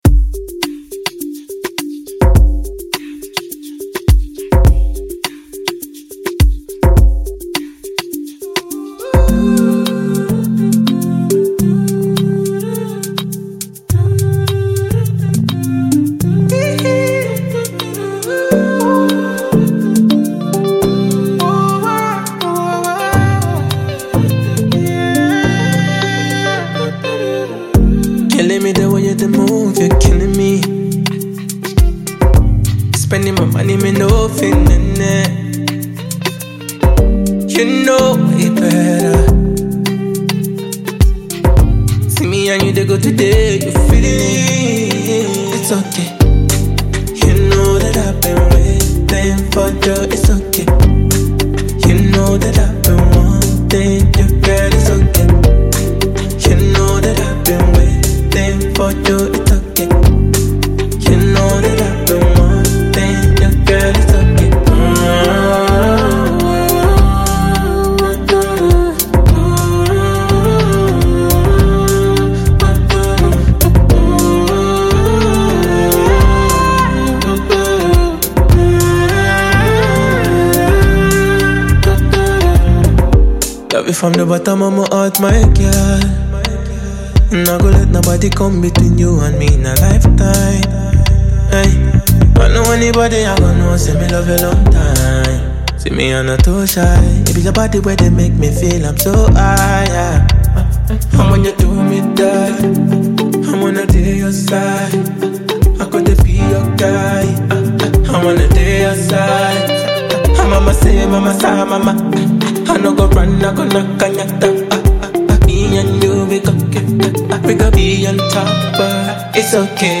dancehall and reggae